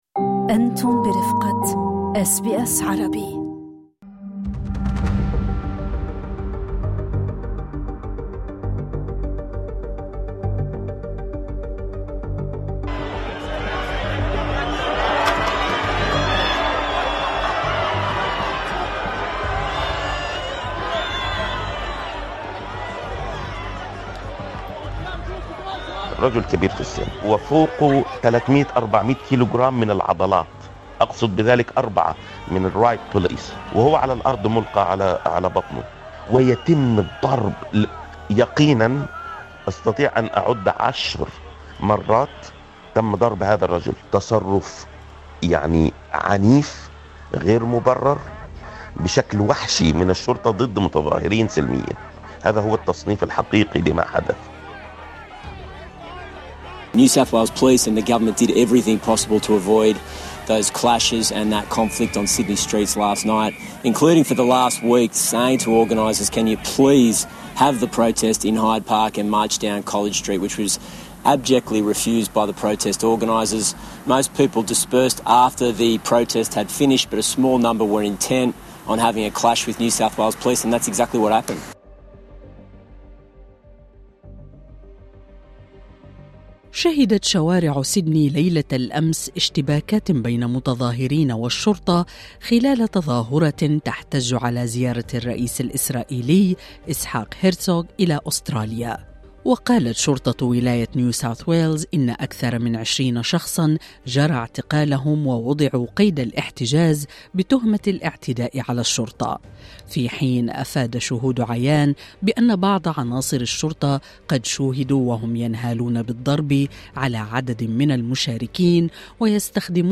كان العضو المستقل في مجلس مدينة كمبرلاند، أحمد عوف مشاركا في التظاهرة وتحدث لاس بي اس عربي عما وصفه بعنف الشرطة الوحشي تجاه المتظاهرين